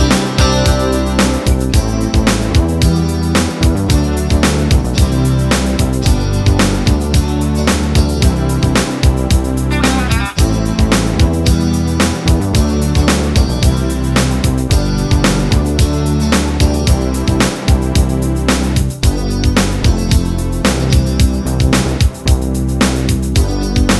no piano Pop (1980s) 4:43 Buy £1.50